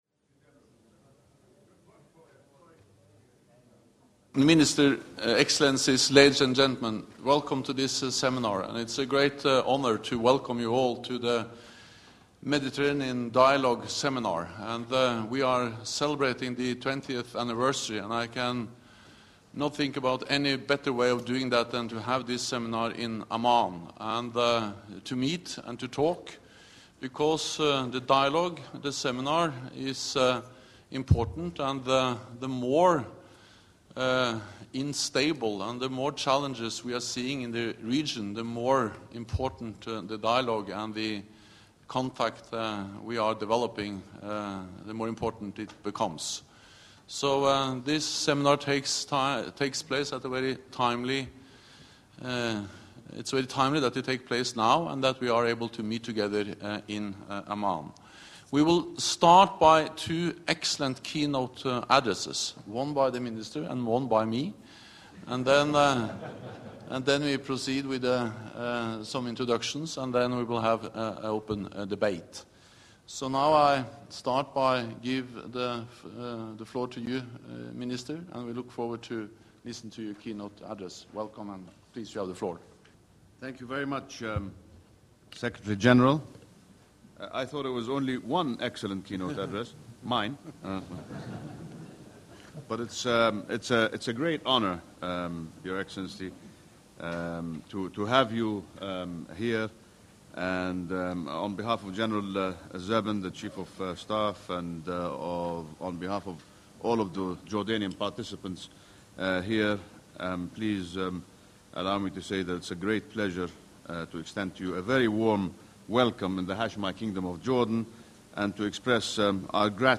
Joint press point with NATO Secretary General Jens Stoltenberg and Minister of Foreign Affairs Nasser Judeh of the Hashemite Kingdom of Jordan 09 Dec. 2014 | download mp3 Keynote address by NATO Secretary General Jens Stoltenberg at the NAC-MD Seminar celebrating the 20th anniversary of the Mediterranean Dialogue 09 Dec. 2014 | download mp3